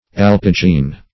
Meaning of alpigene. alpigene synonyms, pronunciation, spelling and more from Free Dictionary.